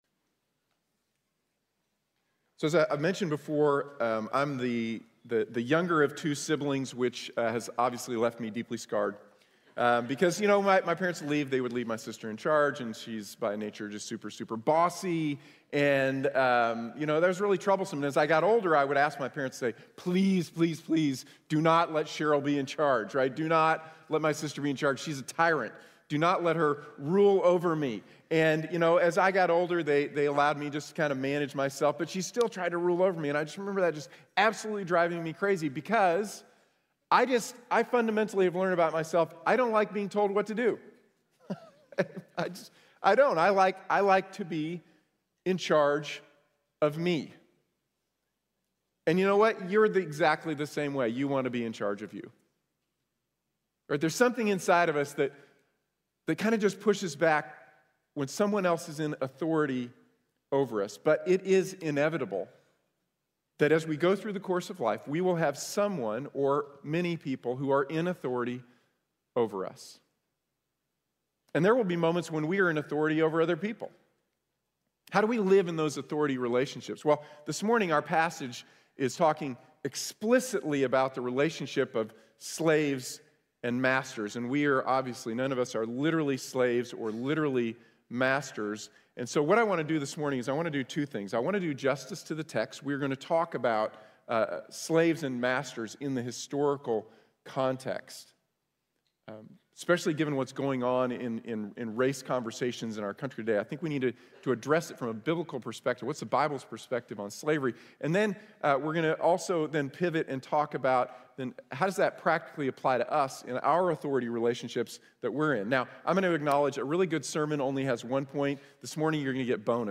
Trabajamos para Jesús | Sermón | Iglesia Bíblica de la Gracia